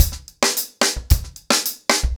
TimeToRun-110BPM.13.wav